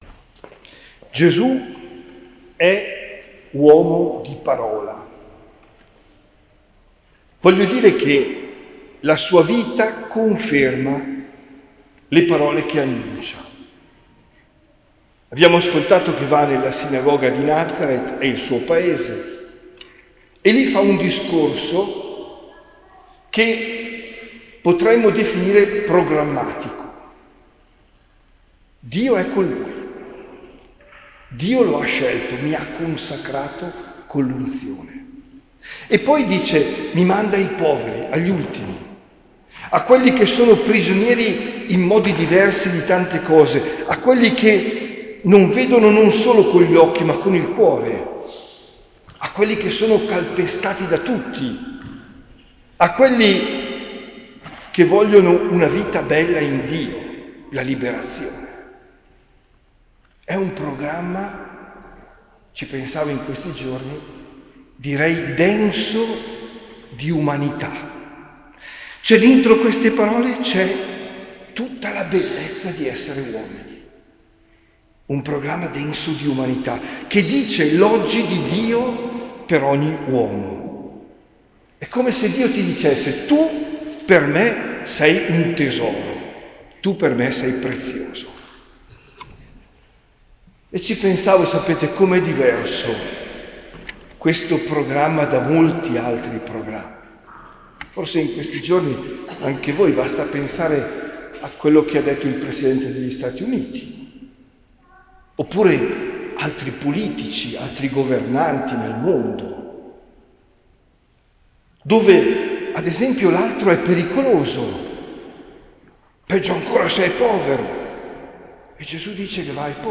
OMELIA DEL 26 GENNAIO 2025